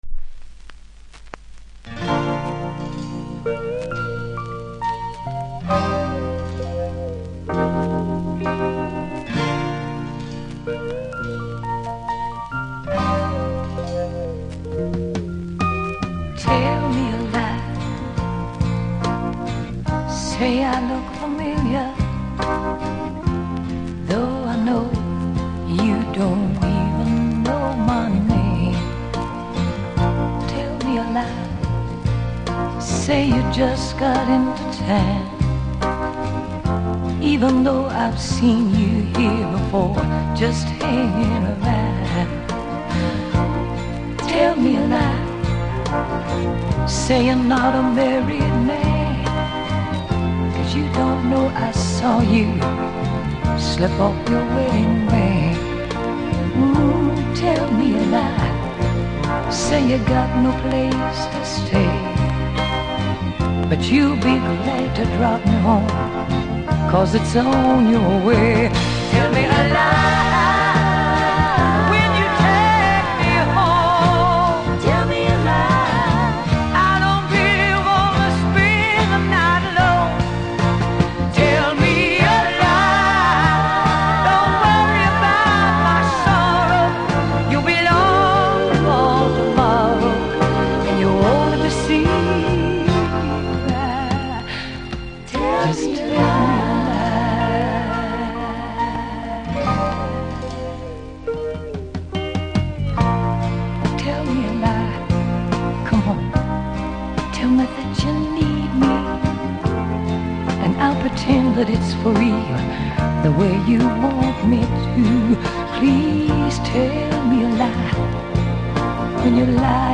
70'S FEMALE
序盤プレス起因のノイズ感じますので試聴で確認下さい。